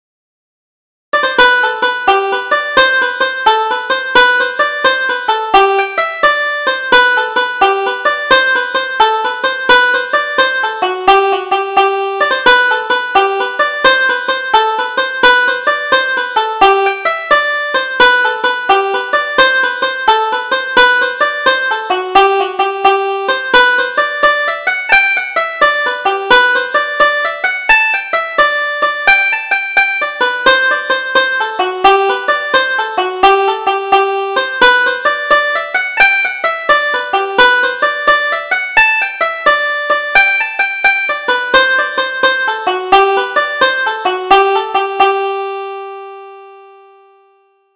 Double Jig: Thrashing the Barley